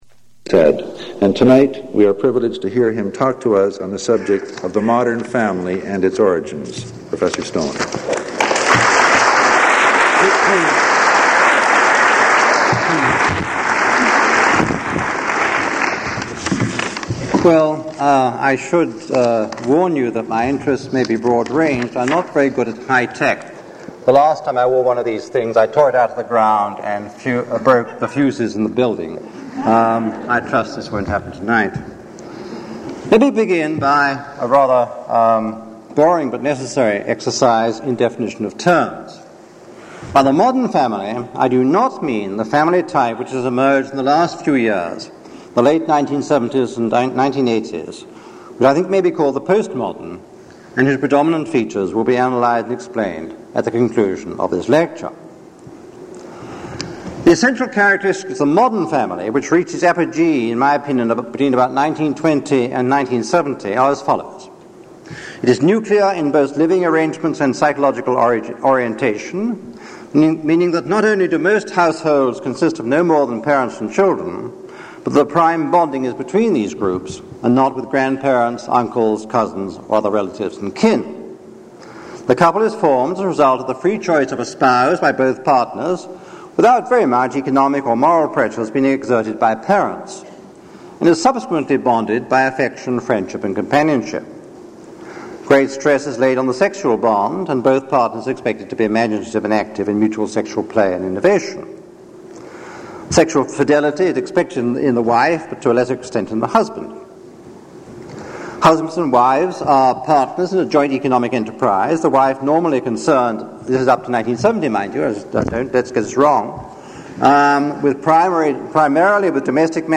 Item consists of a digitized copy of an audio recording of a Dal Grauer Memorial lecture delivered at the Vancouver Institute by Lawrence Stone on January 23, 1982.